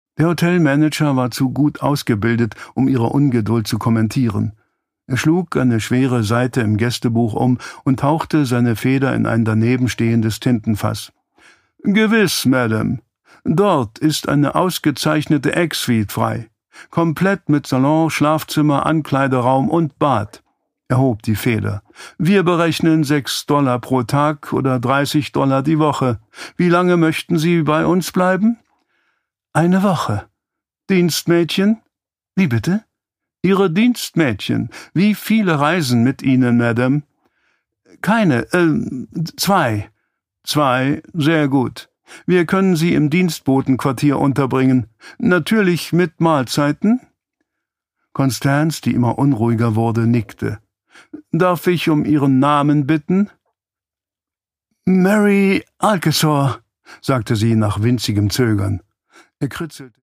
Produkttyp: Hörbuch-Download
Gelesen von: Detlef Bierstedt